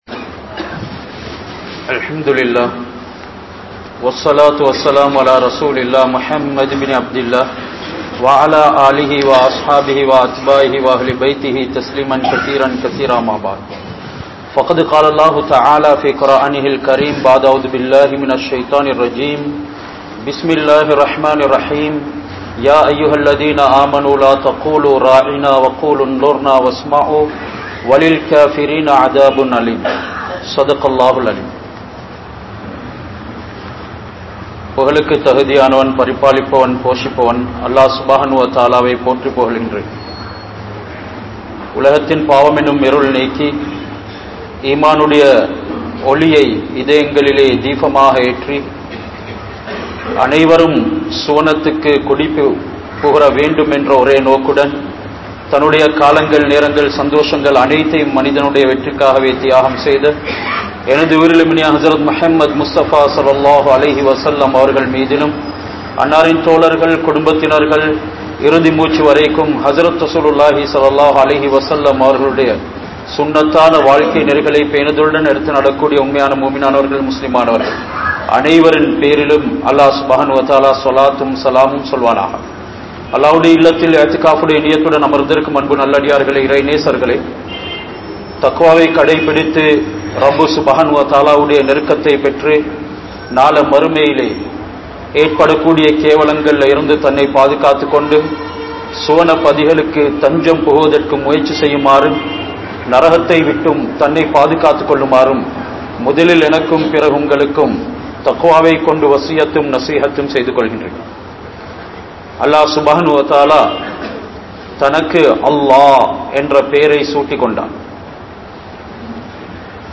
Peyarin Thaakkam Pillahalil (பெயரின் தாக்கம் பிள்ளைகளில்) | Audio Bayans | All Ceylon Muslim Youth Community | Addalaichenai
King Street Jumua Masjidh